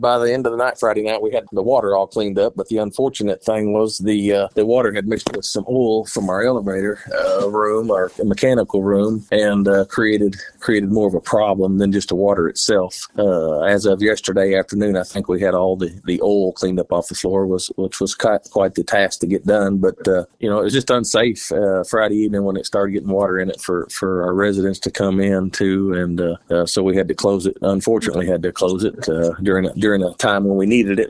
Baxter County Judge Kevin Litty says the water from the flooding was not the main problem causing the closing.